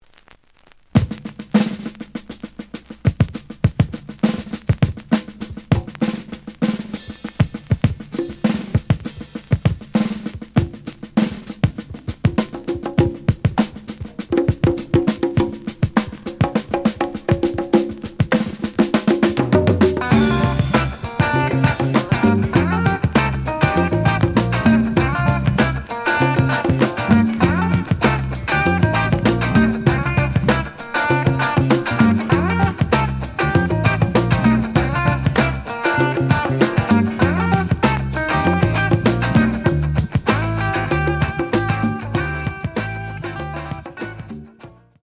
(Instrumental)
The audio sample is recorded from the actual item.
●Genre: Rock / Pop